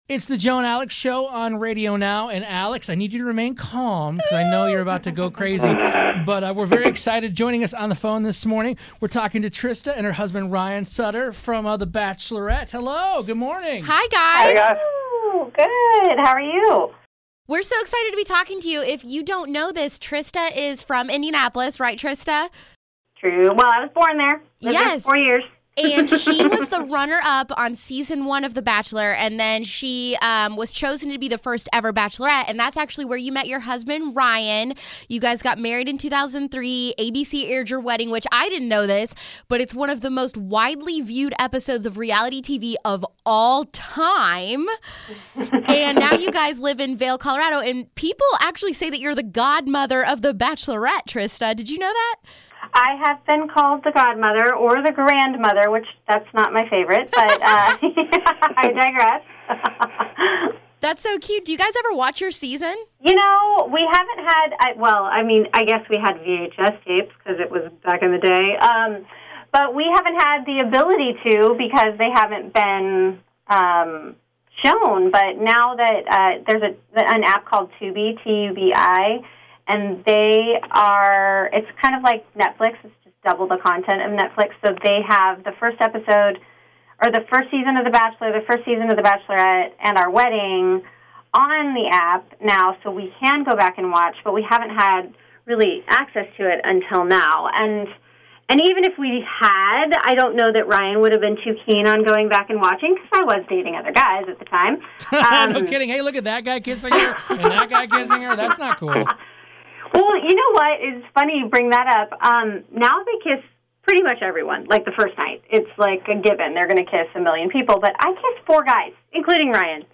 INTERVIEW: The Bachelor's Trista & Ryan Sutter